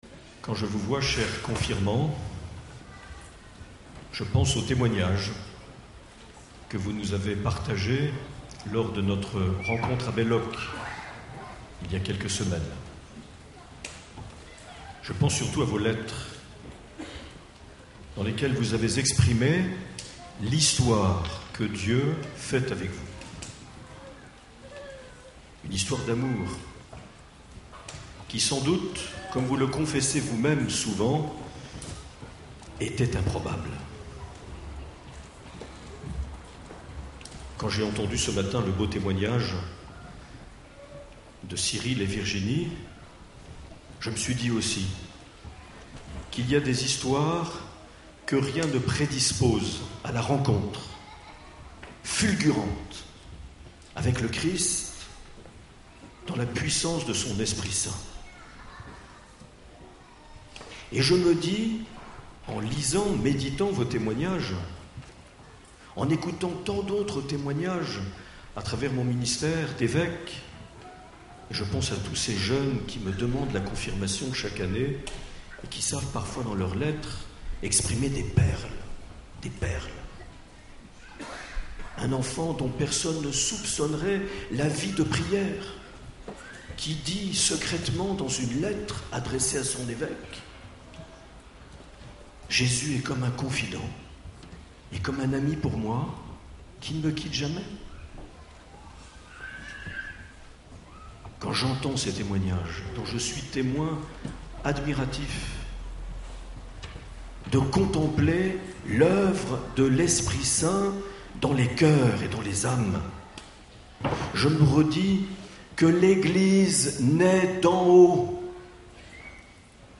8 juin 2014 - Pau - Diocèse en fête - Pentecôte - Consécration du diocèse
Accueil \ Emissions \ Vie de l’Eglise \ Evêque \ Les Homélies \ 8 juin 2014 - Pau - Diocèse en fête - Pentecôte - Consécration du (...)
Une émission présentée par Monseigneur Marc Aillet